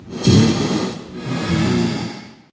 breathe4.ogg